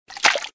TT_splash1.ogg